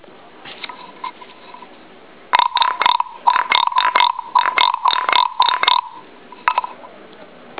Wooden Frog Instrument
• Sounds like a real frog!
• Made of Monkey Pod Wood
6923_Sound-Clip_wooden-frog-1.wav